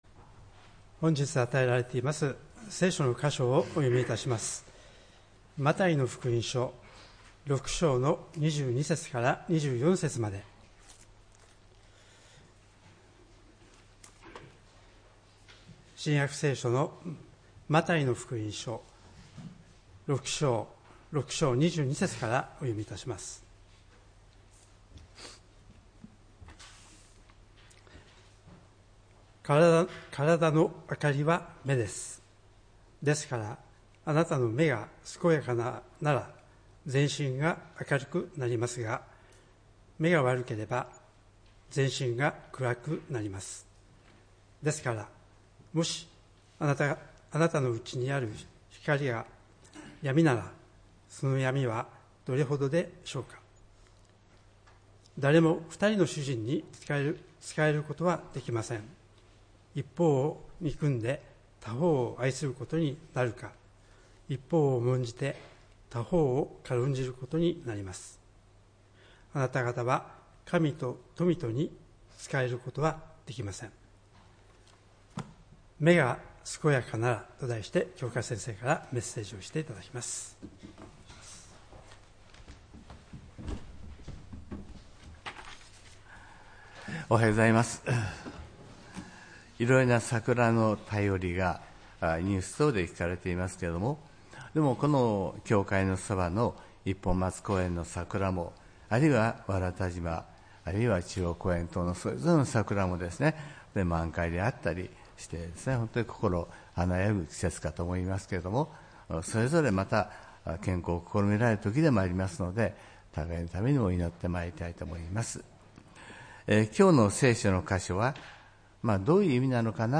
礼拝メッセージ「目が健やかなら」(４月６日）